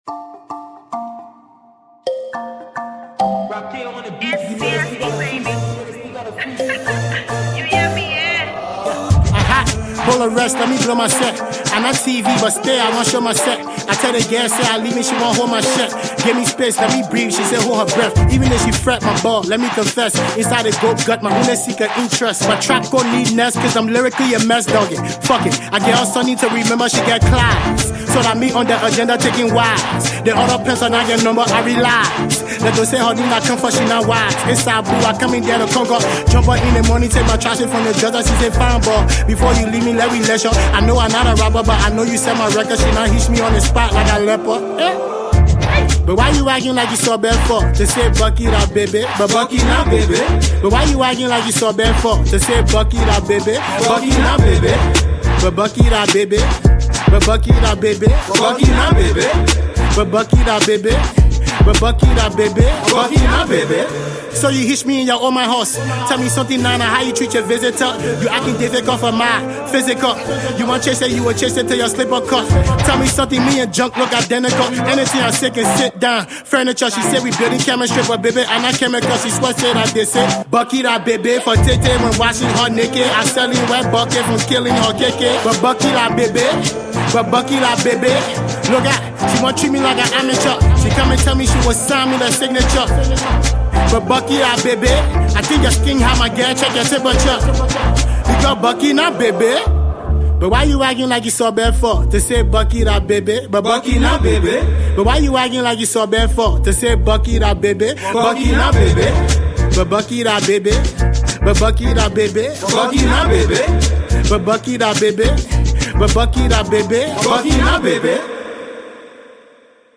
Categories: Hip-CoHip-HopLiberian Music
” offering fans a softer, more playful side.
Tags: Hip-coHiphopTrap-co